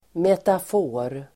Ladda ner uttalet
Uttal: [metaf'o:r]